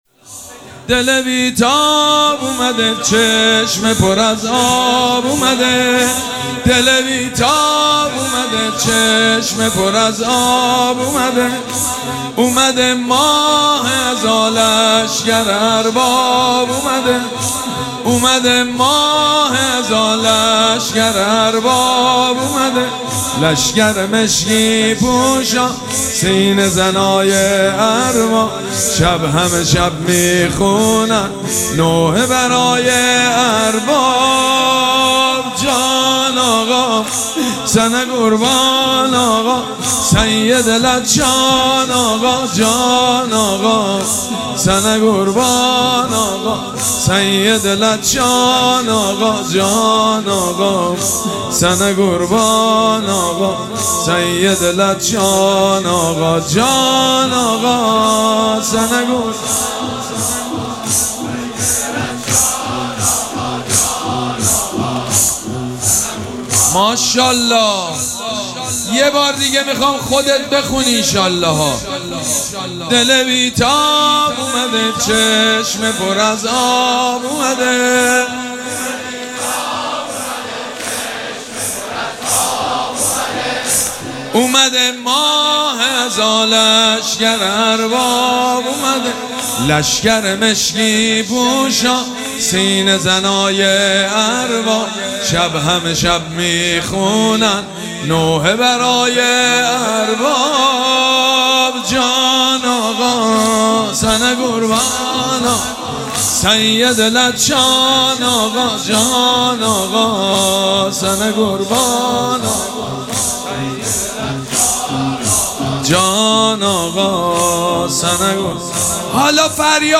شب اول مراسم عزاداری اربعین حسینی ۱۴۴۷